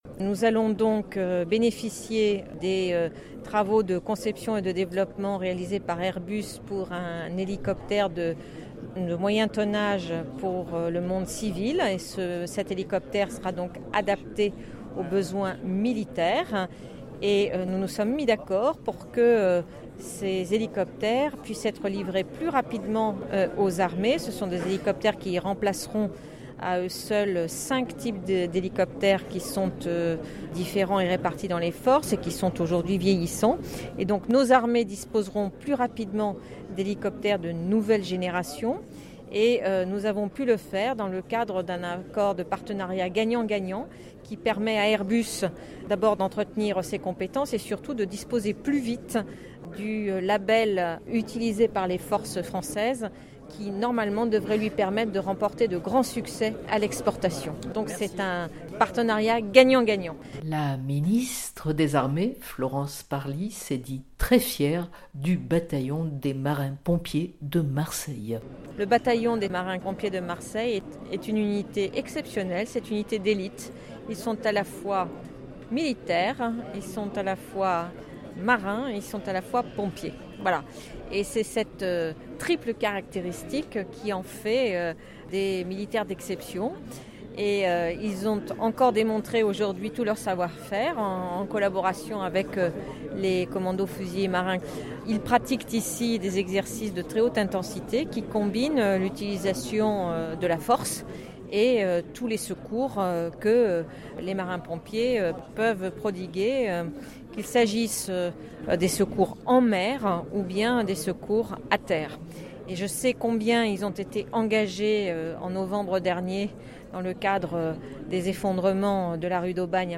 Dans son allocution devant les autorités civiles et militaires ainsi que des représentants de l’unité, Florence Parly a souligné l’engagement triple du Bataillon et l’excellence unique de «ses hommes et de ses femmes, qui sont à la fois militaires, marins et pompiers.»